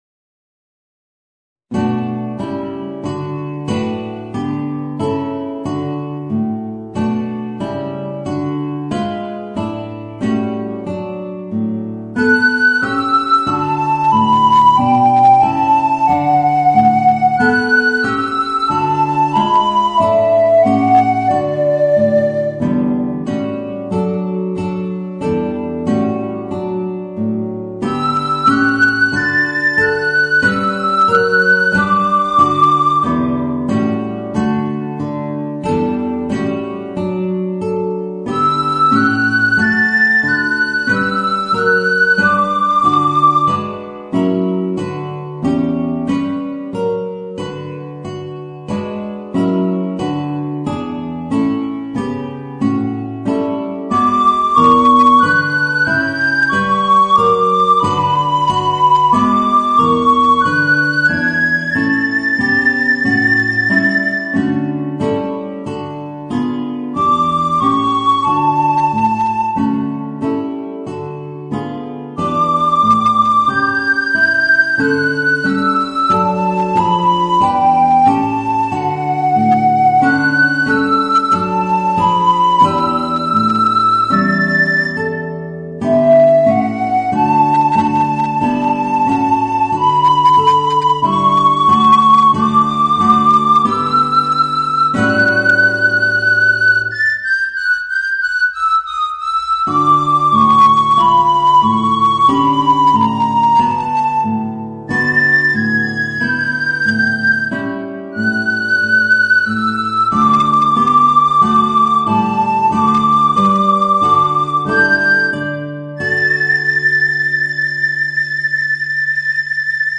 Voicing: Soprano Recorder and Organ